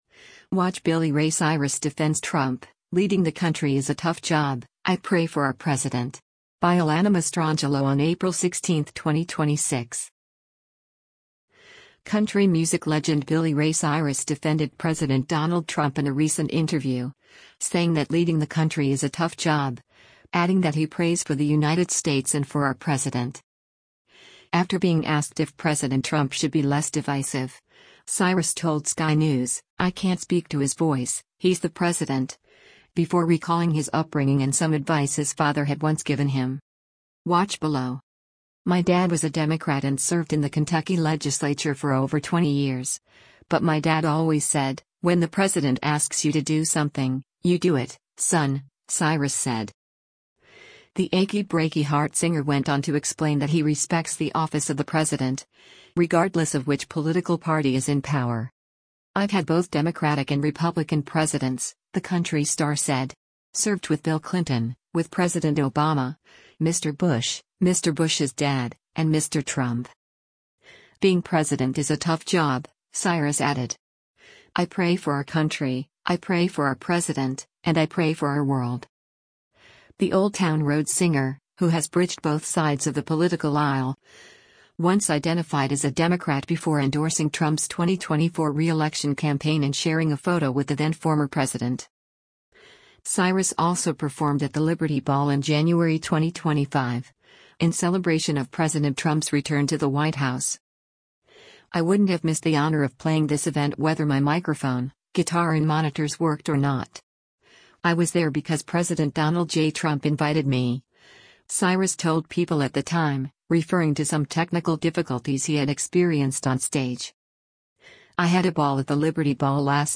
Country music legend Billy Ray Cyrus defended President Donald Trump in a recent interview, saying that leading the country “is a tough job,” adding that he prays for the United States and “for our president.”